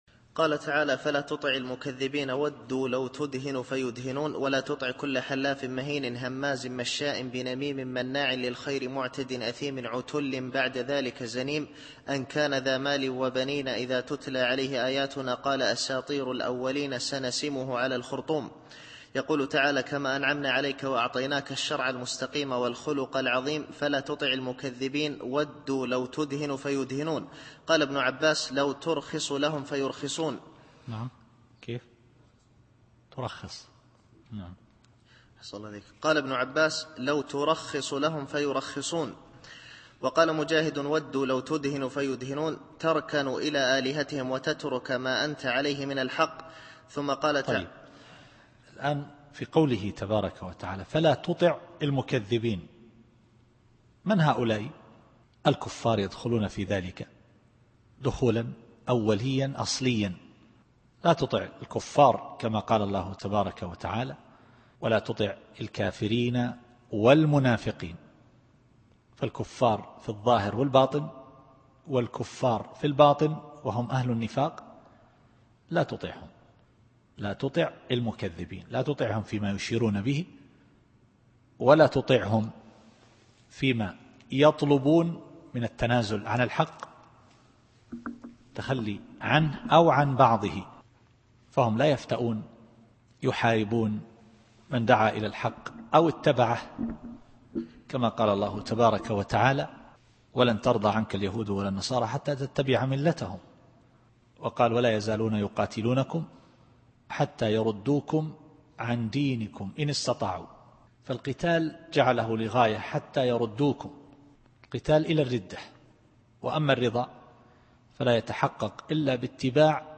التفسير الصوتي [القلم / 9]